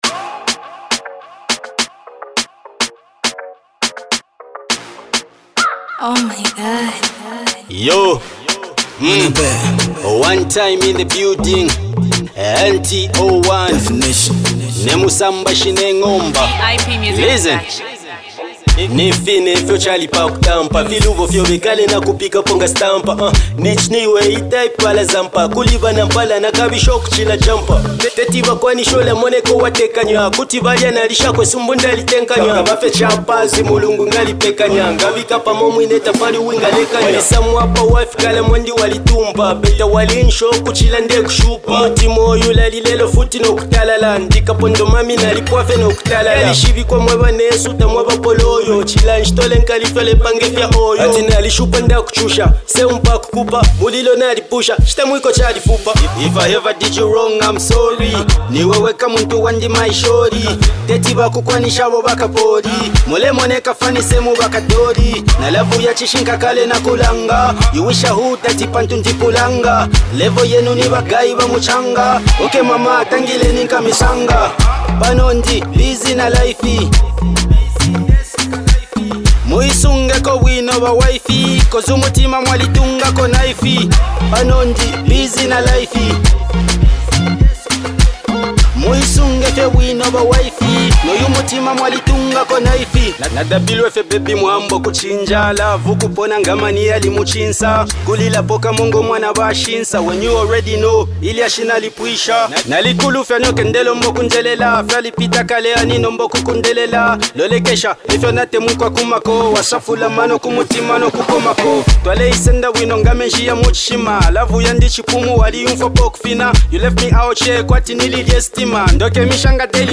feel-good banger